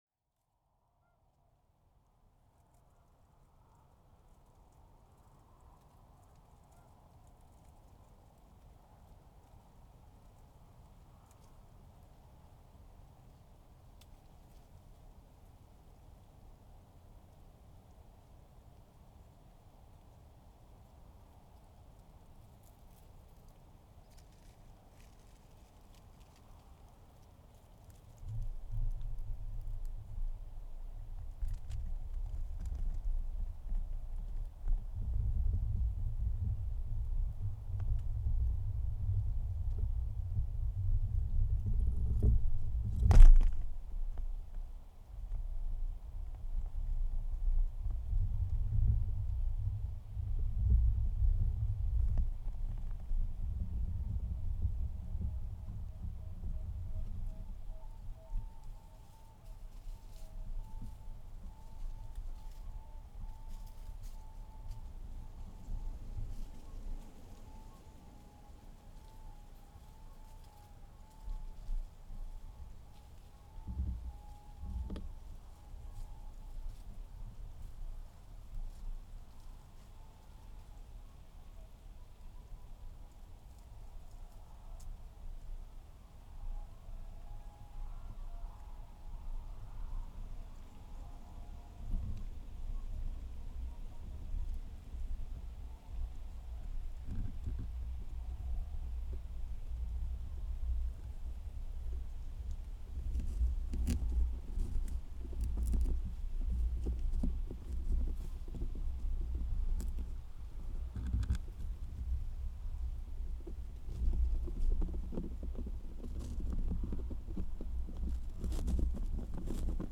But it happened last weekend when I was recording at my traditional place in Stafholtstungur in western Iceland.
This time it was mice that seemed to gnaw the rubber on the tripod or the cables that ran up to the microphones.
Instead, they started gnawing on the cake, which you can clearly hear after I brought the slice of cake. This is traditional night silence. The background noise is mainly the wind in the leaves as well as the traffic of individual cars several kilometers away. I did not clean the internal mic noise in this recording as there is no need for it. Here it was recorded with 48dB gain on SD788 and in post-processing the volume was increased by +10dB up to peak -5dB.